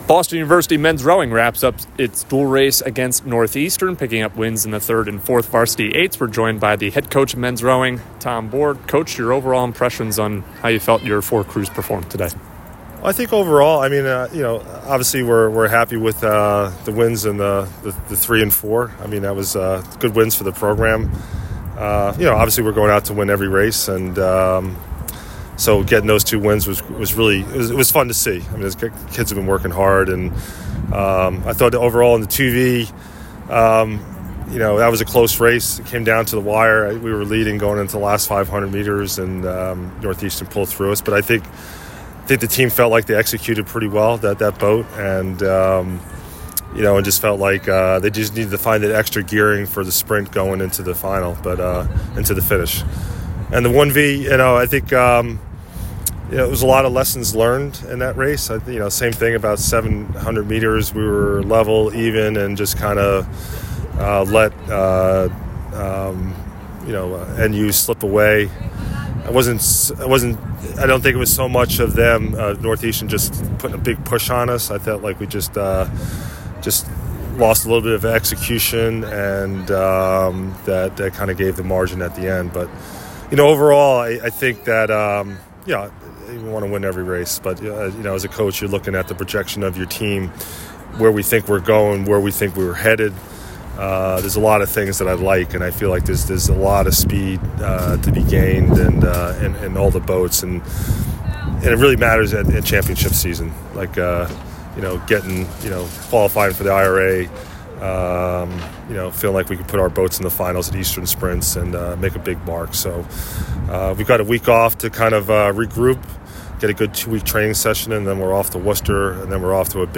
Men's Rowing / Northeastern Postrace Interview